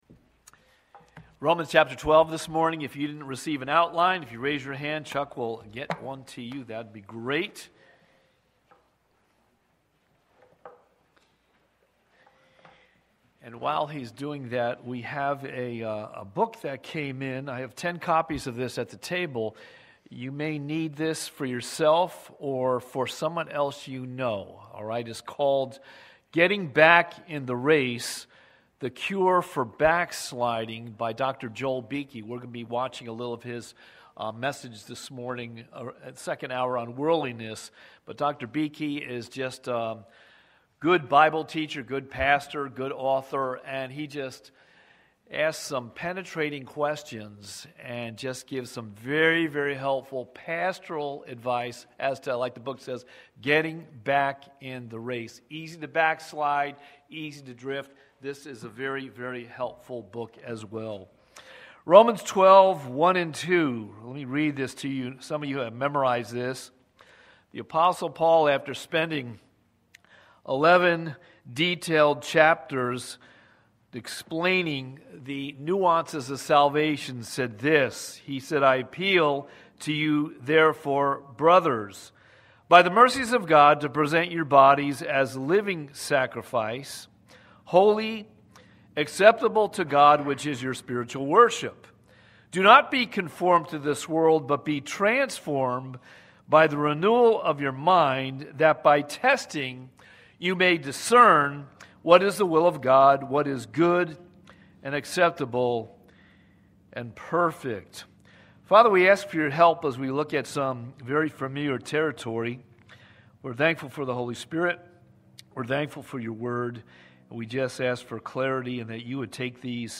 Watch Online Service recorded at 9:45 Sunday morning.